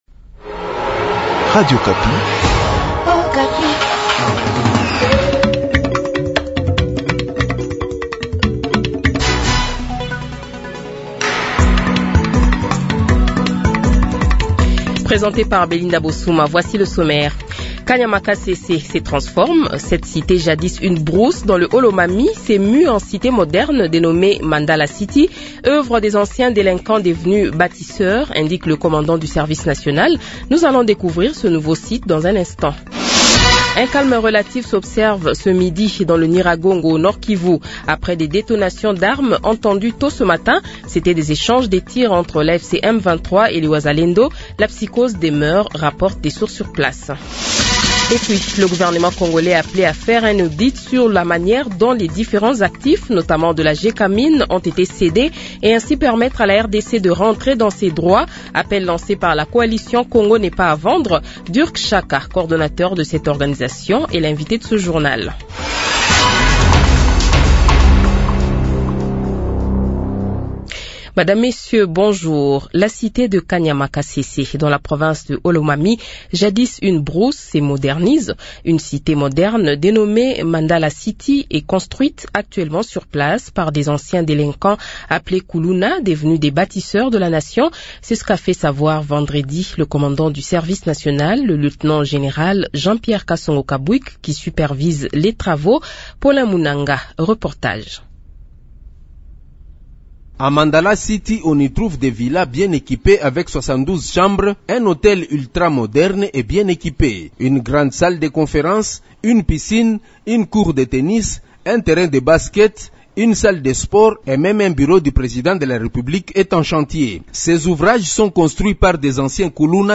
Journal Francais Midi